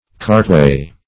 Cartway \Cart"way`\, n. A way or road for carts.